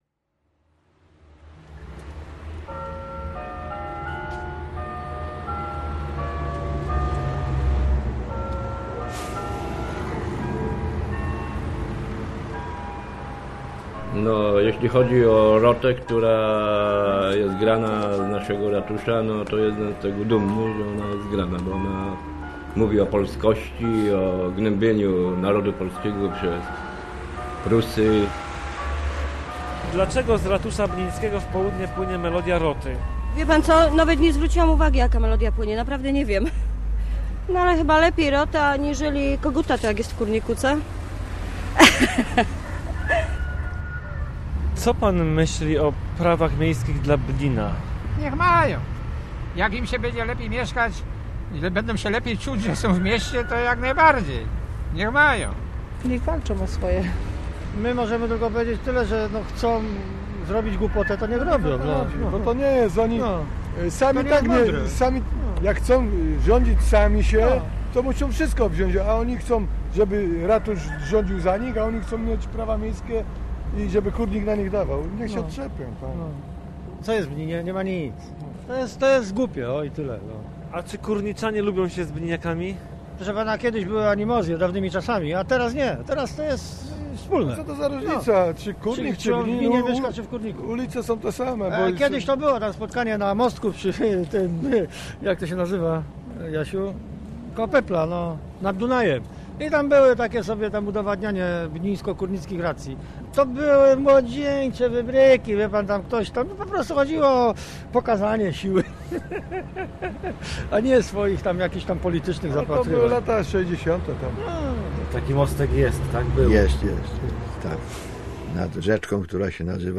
Prawa miejskie Bnina - reportaż